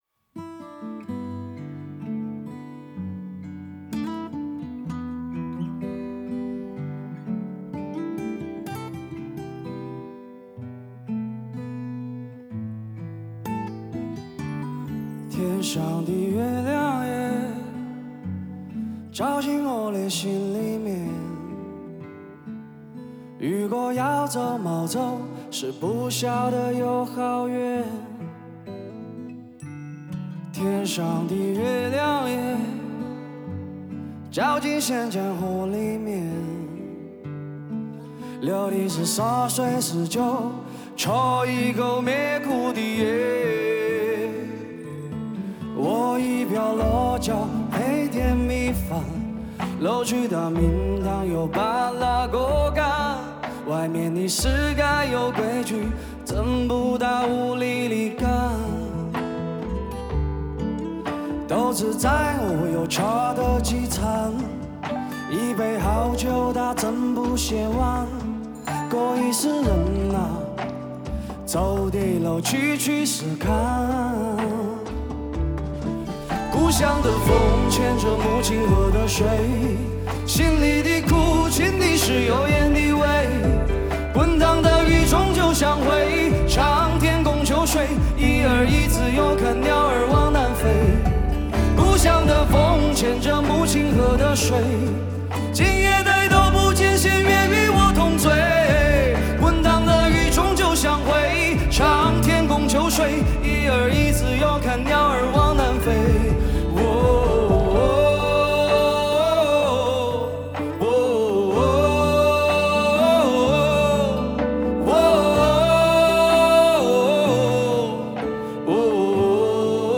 吉他
鼓
贝斯
和声
合唱
弦乐
口琴